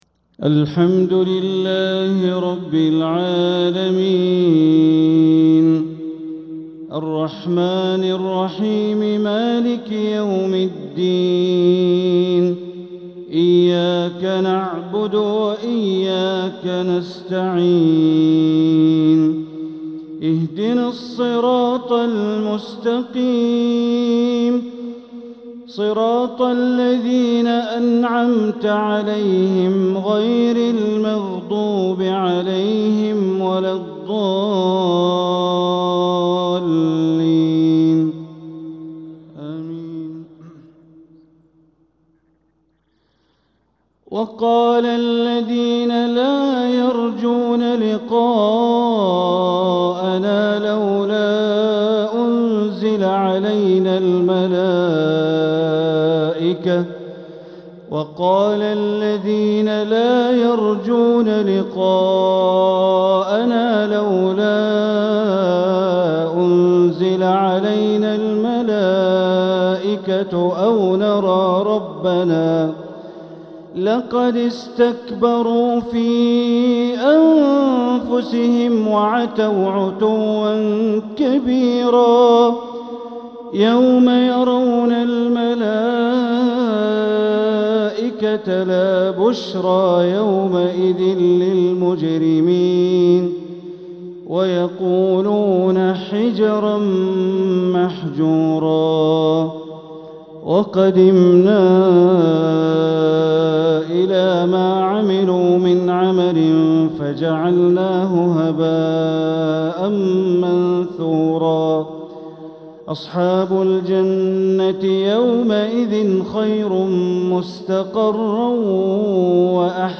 تلاوة من سورة الفرقان عشاء الخميس ٢٩محرم١٤٤٧ > 1447هـ > الفروض - تلاوات بندر بليلة